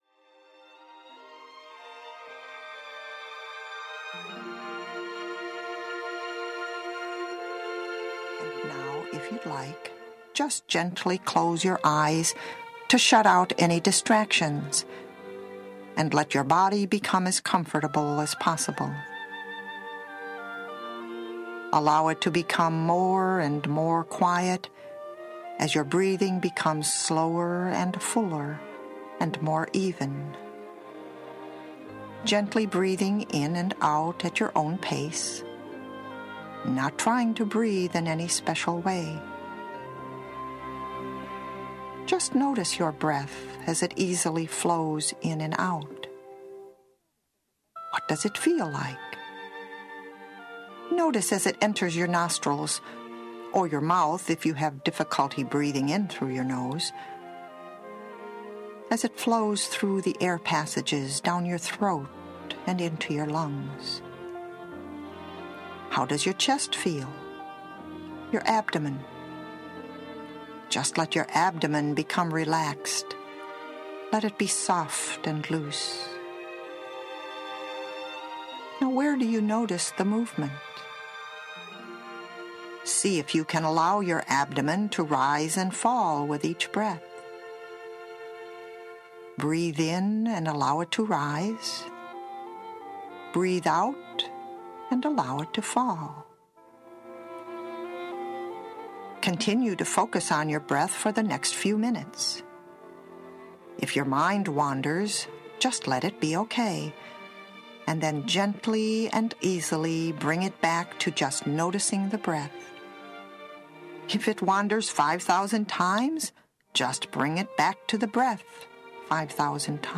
Experience a guidedreset session now.